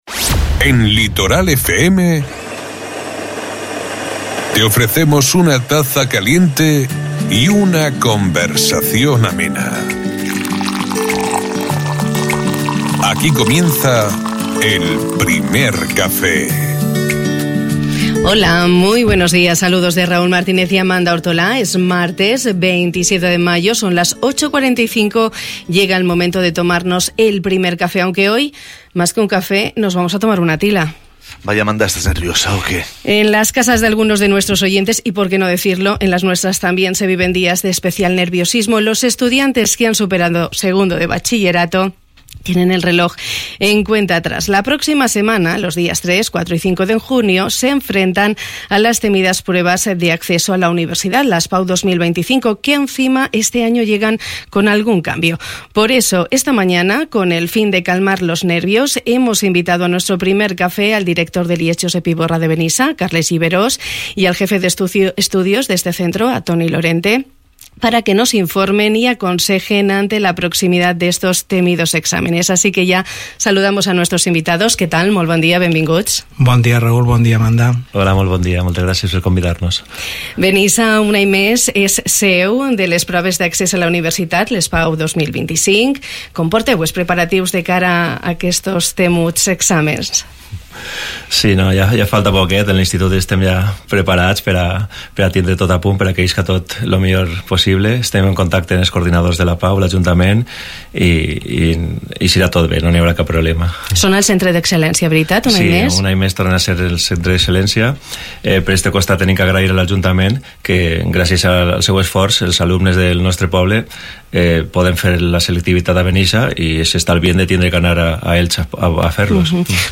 Nuestros invitados han orientado a los estudiantes que en unos días tendrán que realizar estas pruebas, para que las afronten con la mayor tranquilidad posible y consigan el éxito deseado.